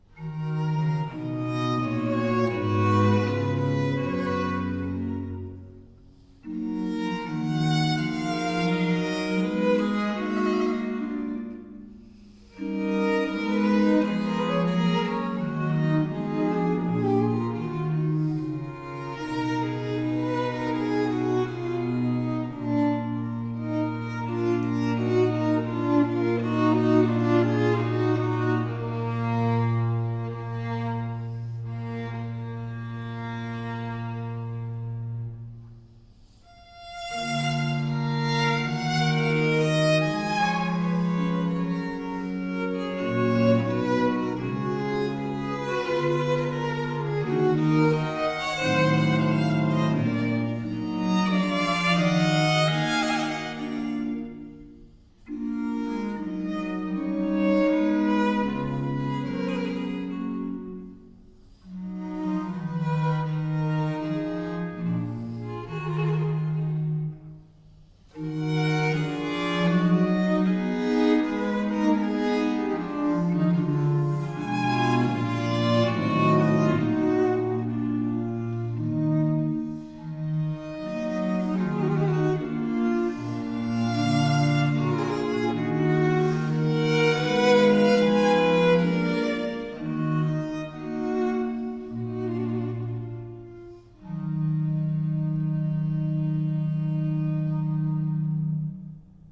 Soundbite 1st Movt
for 2 Violins and Cello
It is in four movements and opens with a stately Largo.